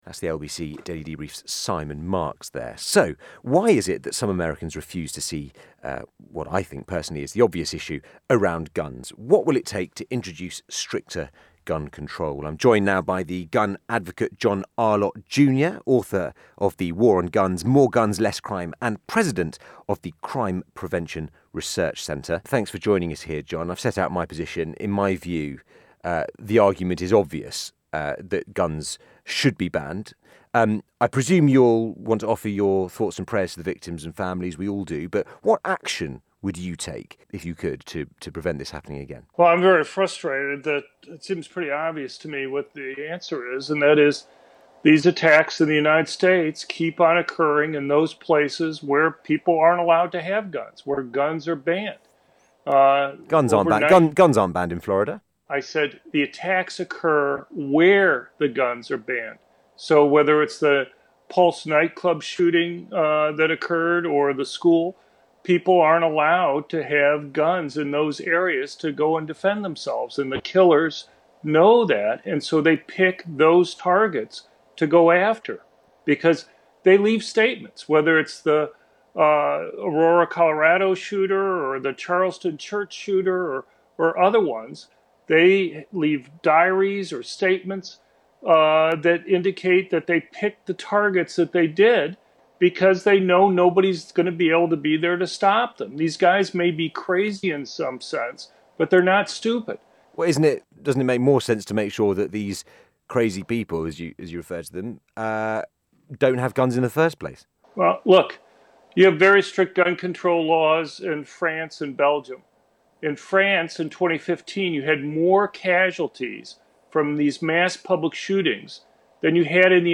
Worth a listen: On Britain's LBC radio to discuss the attack in Florida and gun control in Europe and the US - Crime Prevention Research Center